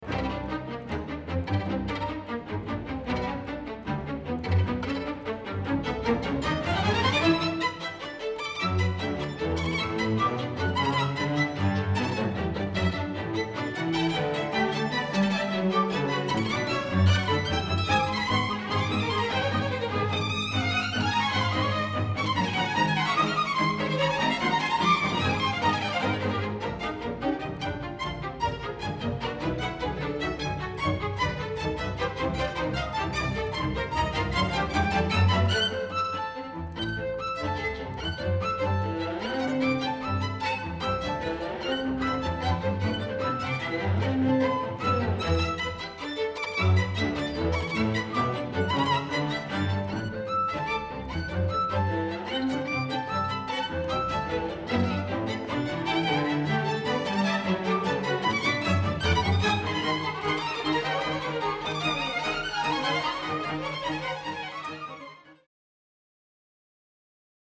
Instrumentation: strings